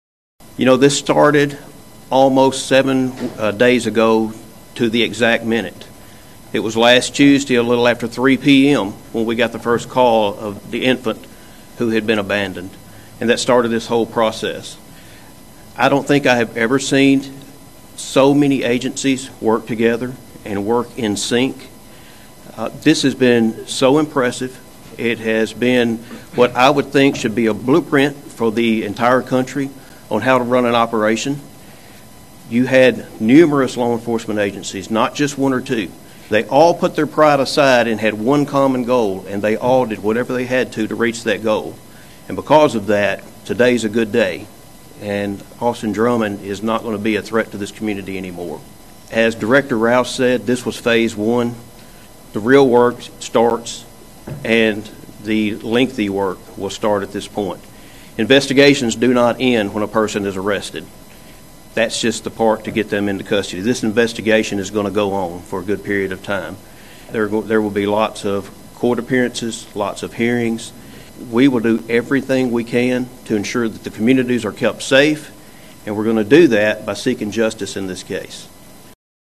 29th Judicial District Attorney General Danny Goodman Jr. praised the many law enforcement officers and agencies dedicated to making an arrest in the case.(AUDIO)